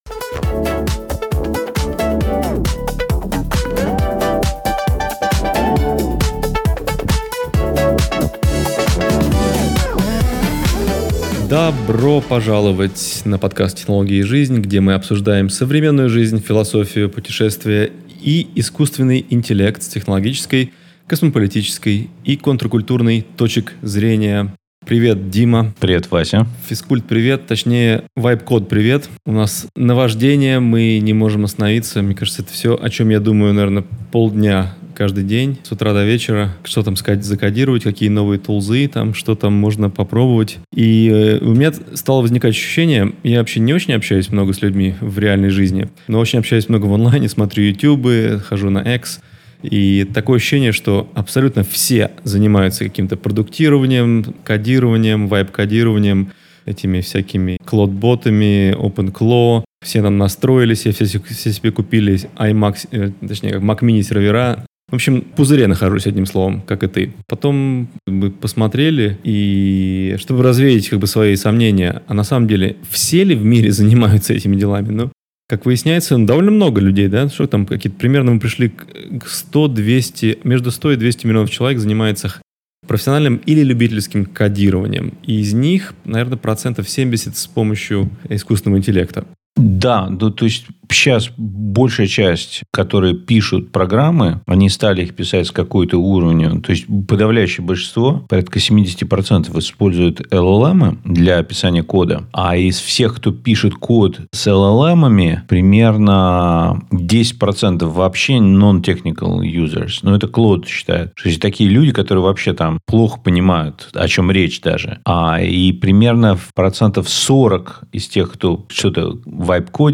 Разговор о том, как нейросетевые агенты превращают обычных людей в разработчиков и что это значит для будущего человечества.